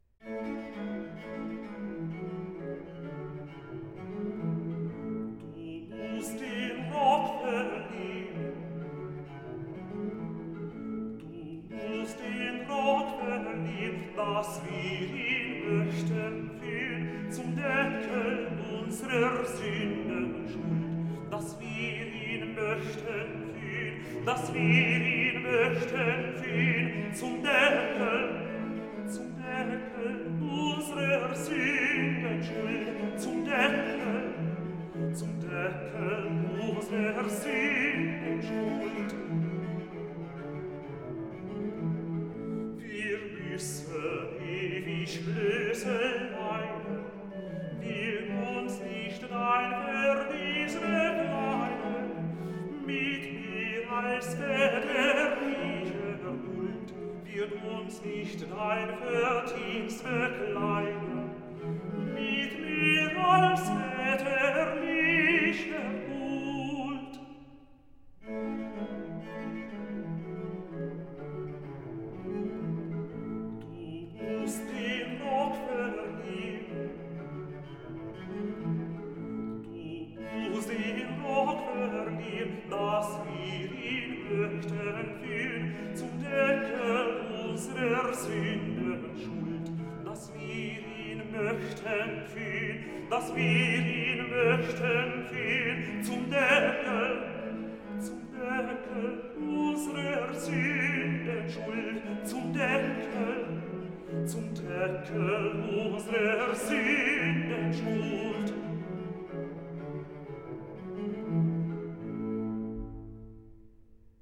Aria tenore